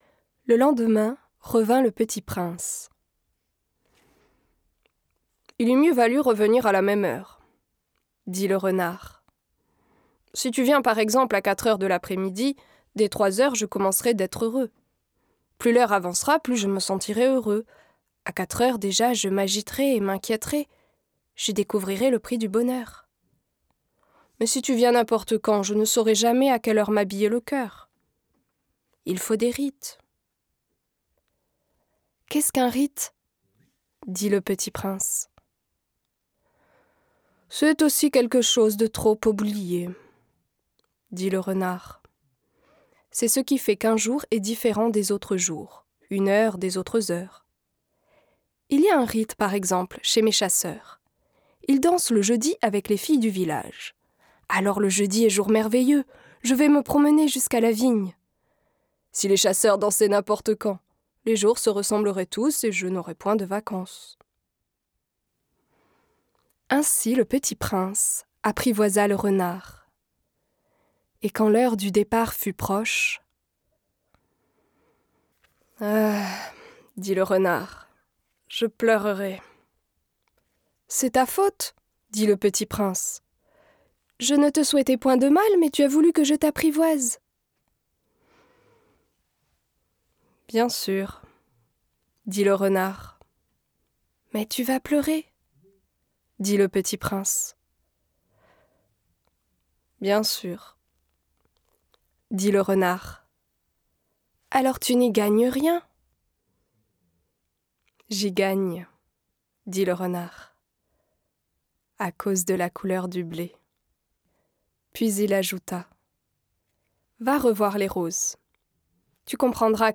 Extrait livre audio
- Soprano